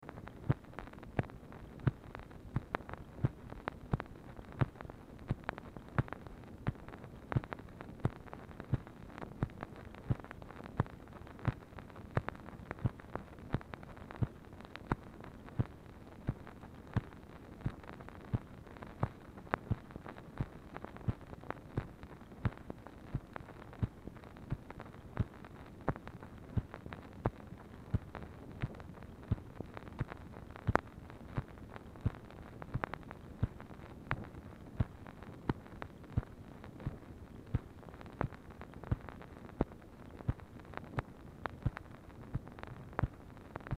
MACHINE NOISE
Oval Office or unknown location
"WH COM"; BLANK NON-GROOVED SEGMENT ON ORIGINAL DICTABELT
Telephone conversation
Dictation belt